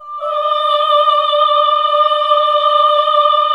AAH D3 -L.wav